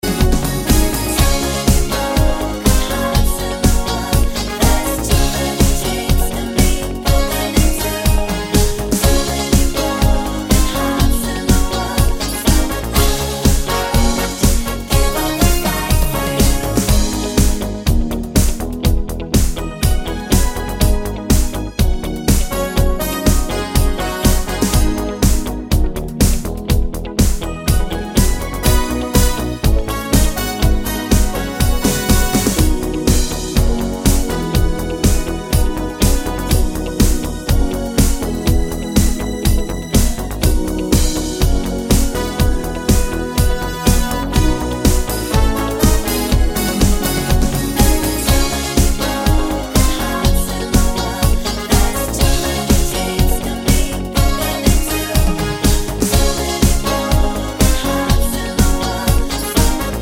Key of B Flat Pop (1980s) 3:16 Buy £1.50